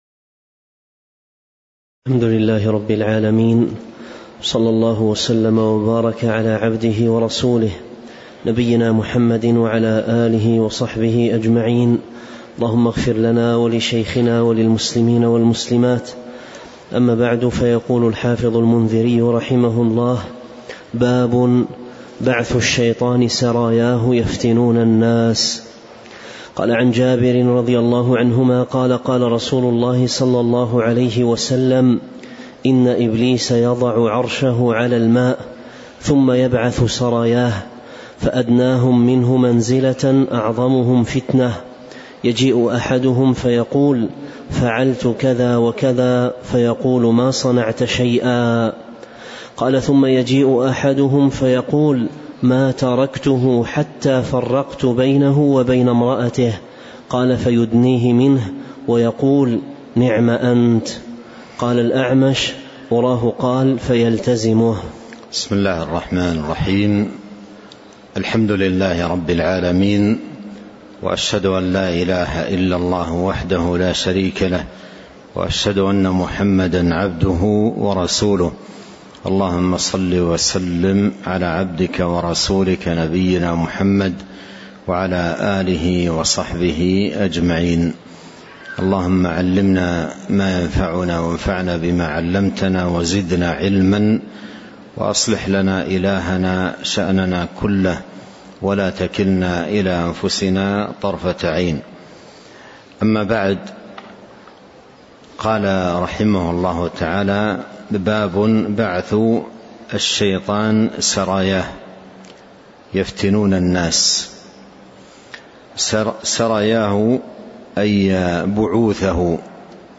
تاريخ النشر ٣ صفر ١٤٤٤ هـ المكان: المسجد النبوي الشيخ: فضيلة الشيخ عبد الرزاق بن عبد المحسن البدر فضيلة الشيخ عبد الرزاق بن عبد المحسن البدر باب بعث الشيطان سراياه يفتنون الناس (04)